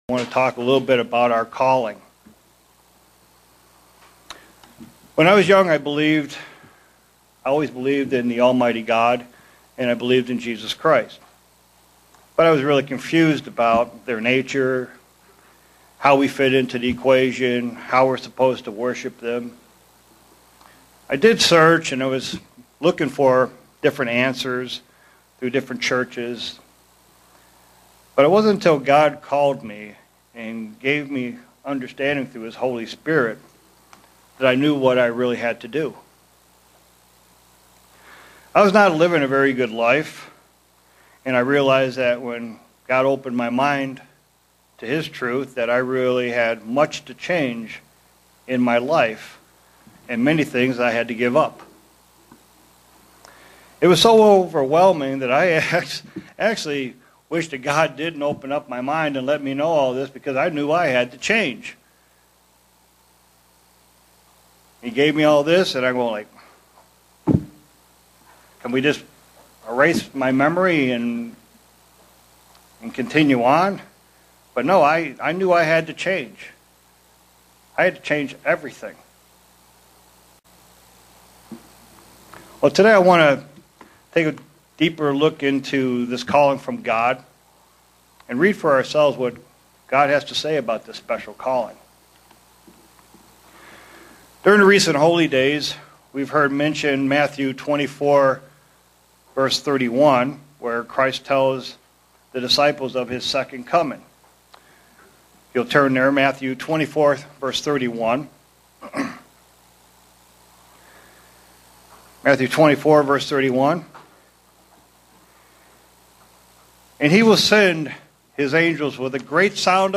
Given in Buffalo, NY
Print How we should look at Gods' Calling and how to keep it. sermon Studying the bible?